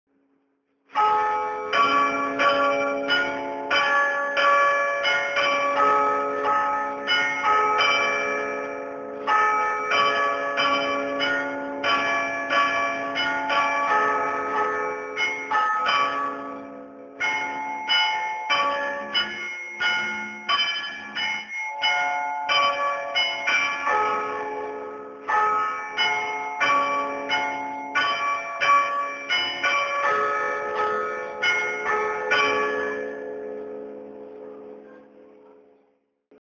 Glocken „Beiern“
Unter „Beiern“ versteht man eine festliche Art des rhytmischen Glockenläutens, wobei sehr viel Kraft, Anstrengung und Gefühl vom „Spieler“ abverlangt werden.
Die Melodien werden nach alten überlieferten Vorlagen oder nach freier Improvisation gespielt.
Aufzeichnung „Glocken- Beiern“ während der Rosenfestprozession